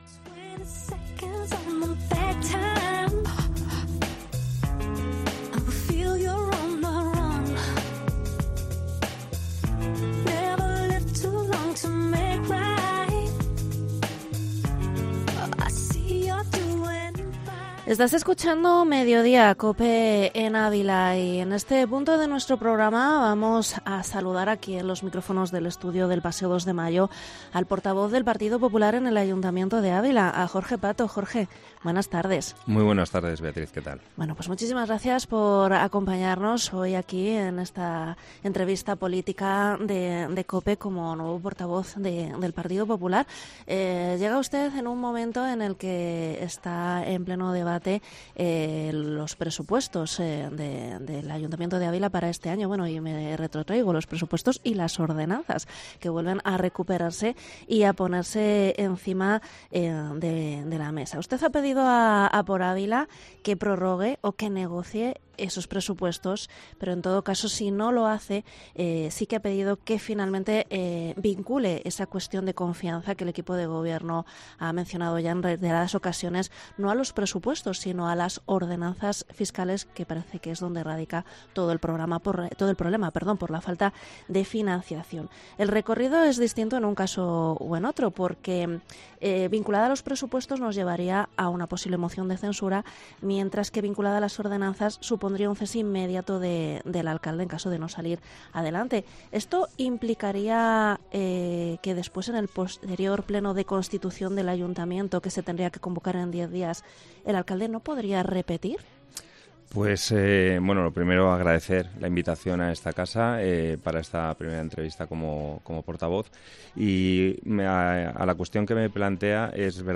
ENTREVISTA
ENTREVISTA al portavoz del PP en el Ayuntamiento, Jorge Pato
Este lunes ha pasado por los micrófonos de Cope Ávila el nuevo portavoz del Partido Popular en el Ayuntamiento de Ávila, Jorge Pato, quien ha aseverado que apoyar el proyecto de Ordenanzas Fiscales que Por Ávila ha vuelto a poner sobre la mesa significaría “dar carta blanca” al equipo de Gobierno para subir los impuestos de cara a 2025. Pato ha indicado que la subida de tasas que propone Por Ávila entraría en vigor a partir del 1 de julio.